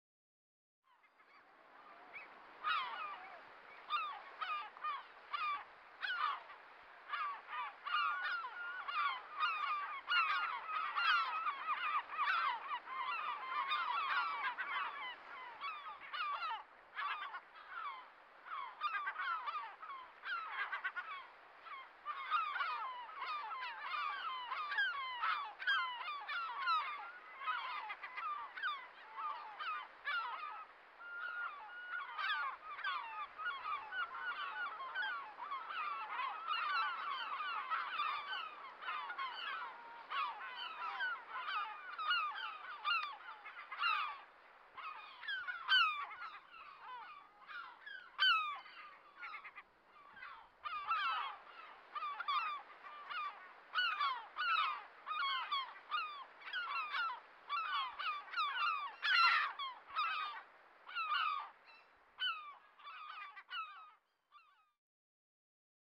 Pitäjänlehdet uutisoivat joka kevät maaliskuisista kurkiauroista, mutta oikeasti noin varhain kuuluvasti kailottavina auroina ja jonoina saapuvat harmaalokit.